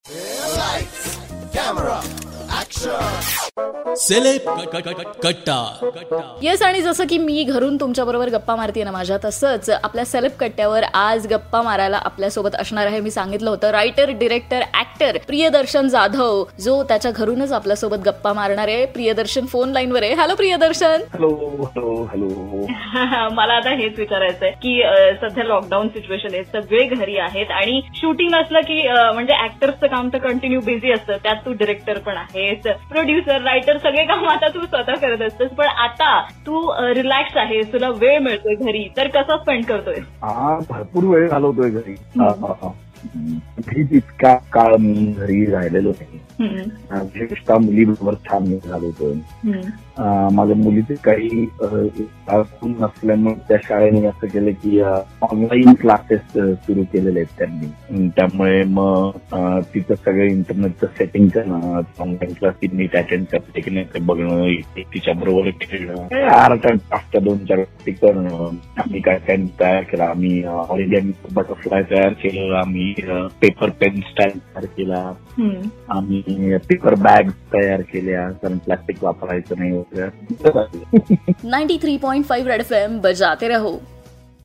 took an interview of famous actor director Priyadarshan Jadhav..In this interview Priyadarshan shared his routine..how he is doing things in this lockdown..